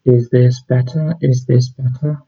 When I speak into my microphone the audio on Audacity is unclear